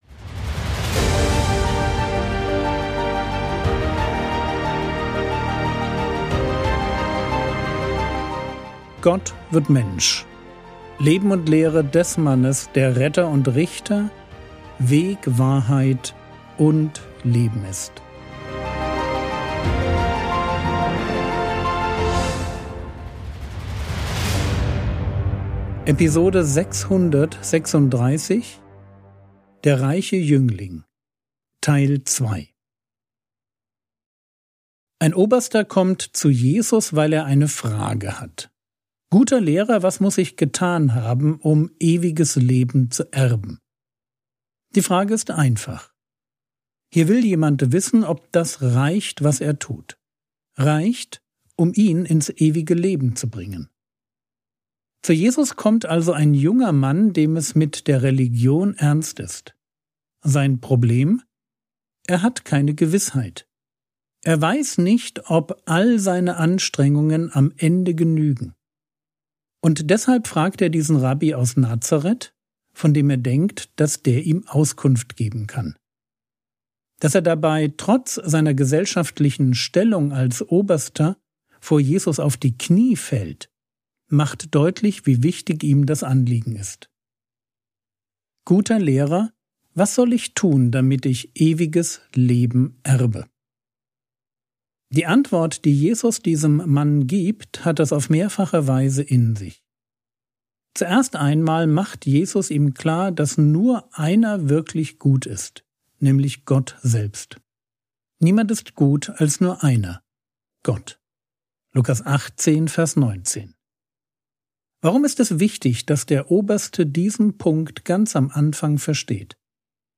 Episode 636 | Jesu Leben und Lehre ~ Frogwords Mini-Predigt Podcast